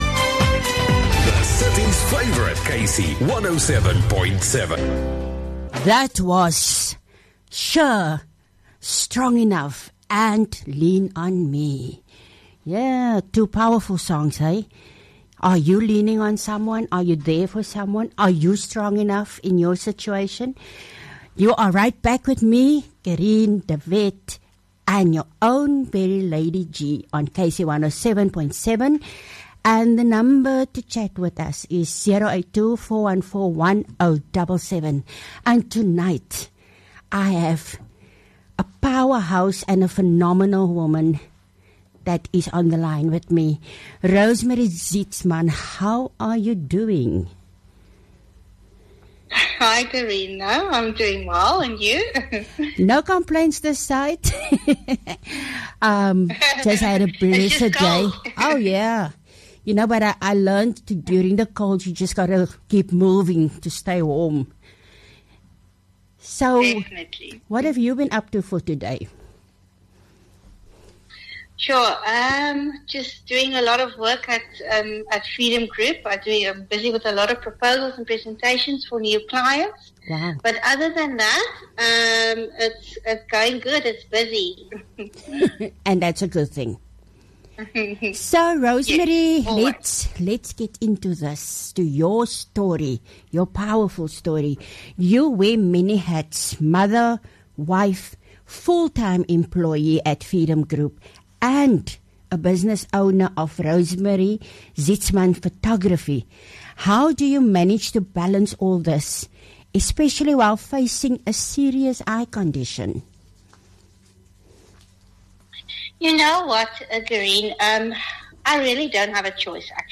Inspirational interview